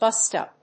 /ˈbʌstʌ(米国英語)/